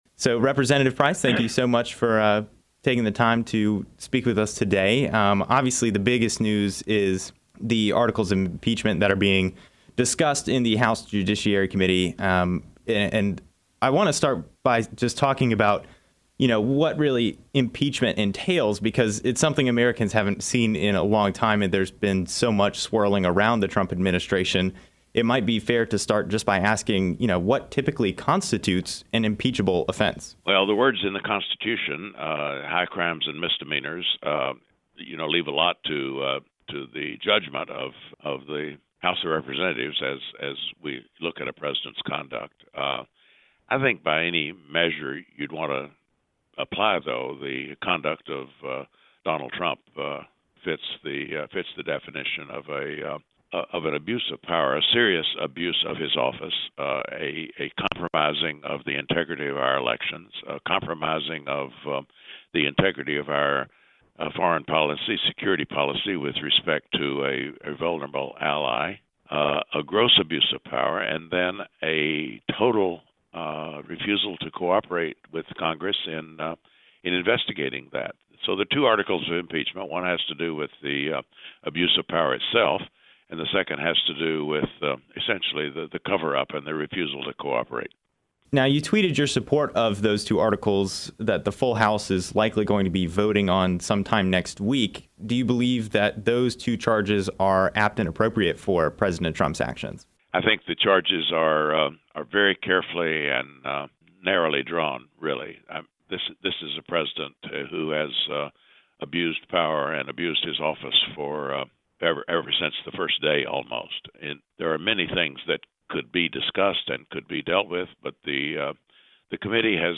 After announcing his support for Donald Trump's impeachment, U.S. Rep. David Price (D-Chapel Hill) discusses the issue